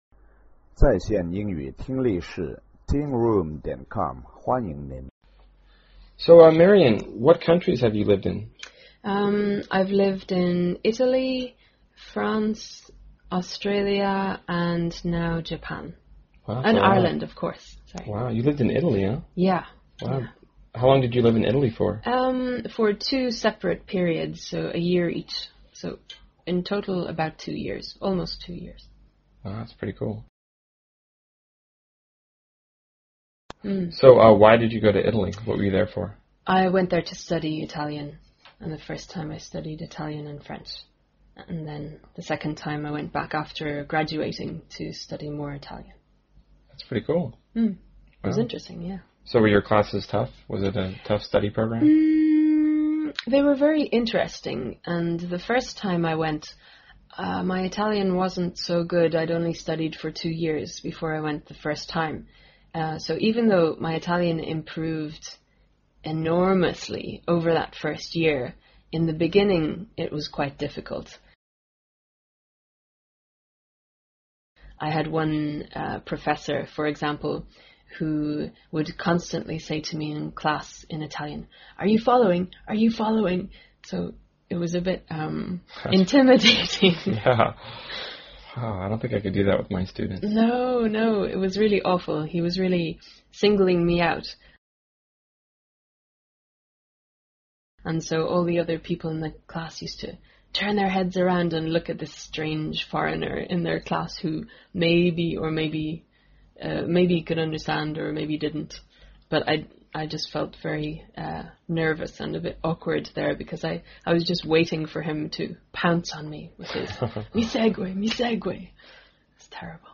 在线英语听力室英文原版对话1000个:587 Life in Italy的听力文件下载,原版英语对话1000个,英语对话,美音英语对话-在线英语听力室